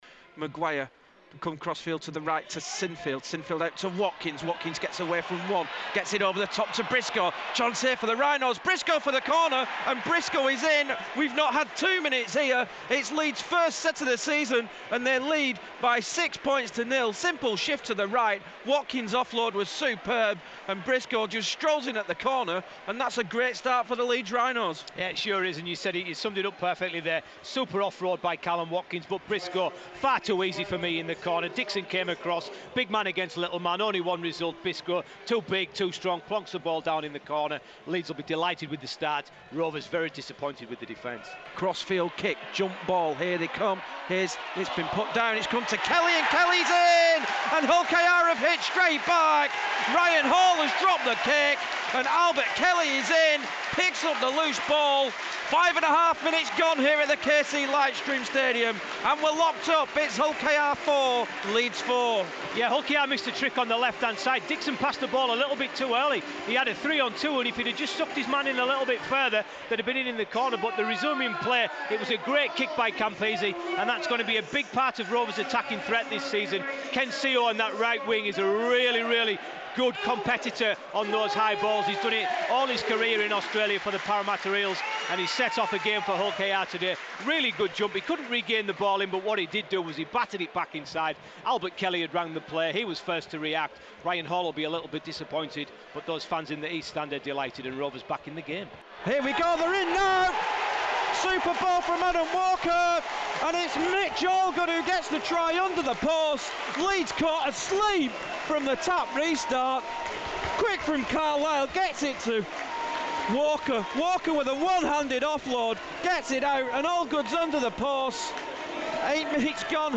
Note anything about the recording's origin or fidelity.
Highlights of a pulsating game between Hull Kingston Rovers and Leeds Rhinos, as heard on Radio Yorkshire's Rugby League Live.